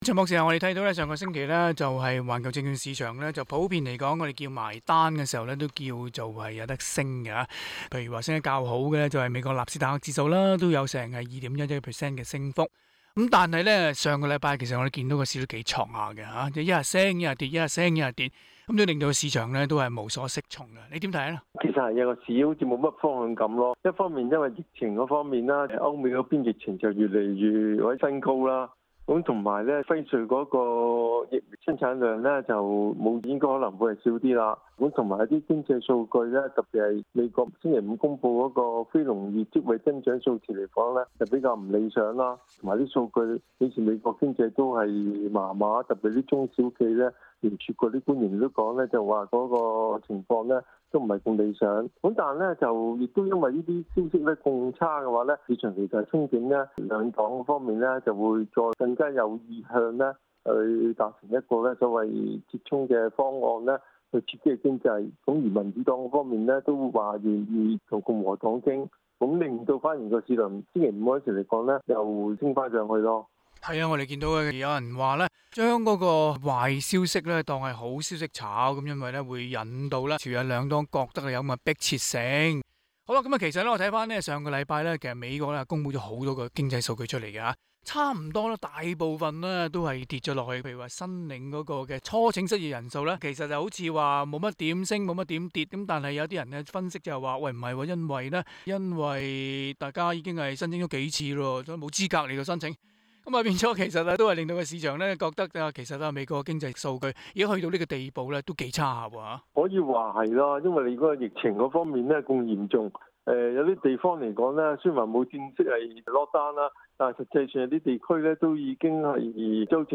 還請大家收聽今日的訪問內容。